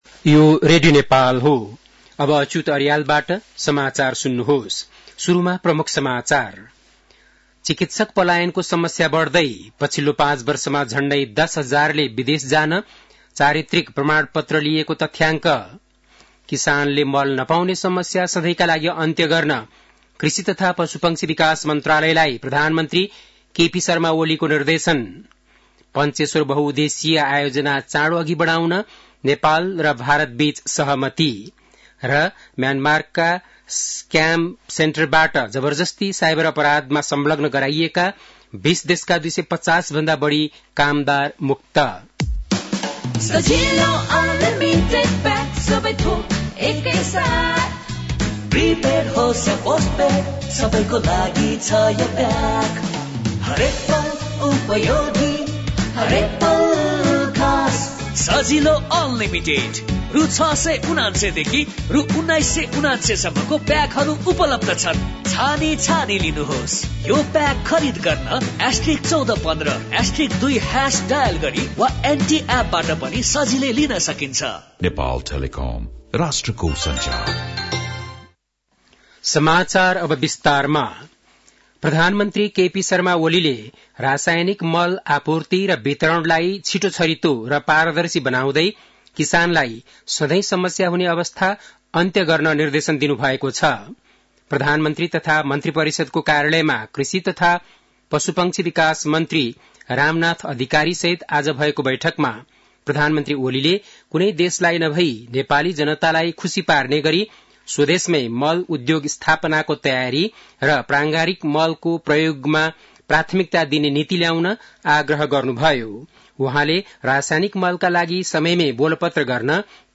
बेलुकी ७ बजेको नेपाली समाचार : २ फागुन , २०८१
7-pm-nepali-news-11-01.mp3